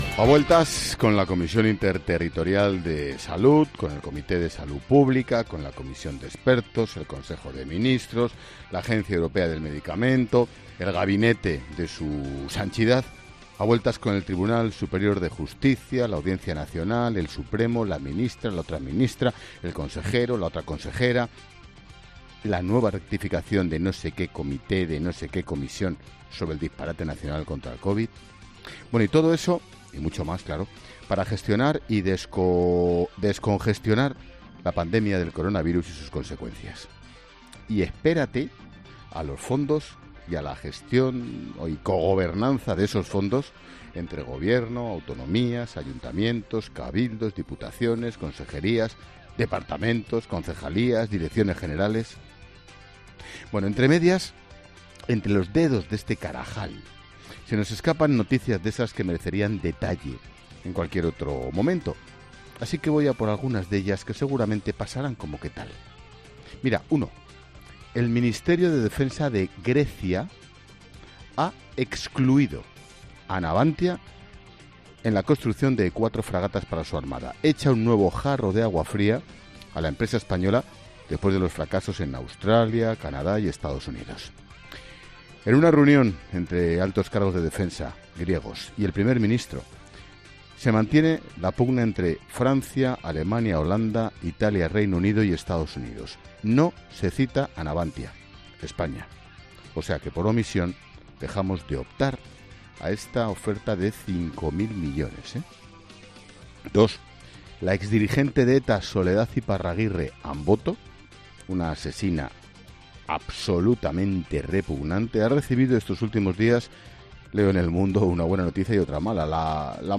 Monólogo de Expósito
El director de 'La Linterna', Ángel Expósito, desglosa en su monólogo las principales noticias que deja este miércoles